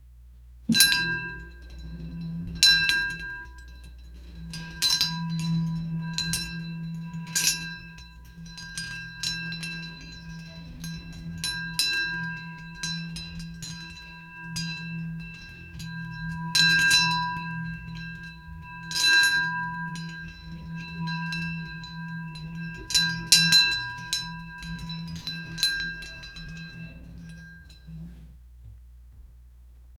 distant-bell-tolls-and-ec-d3n7o3j4.wav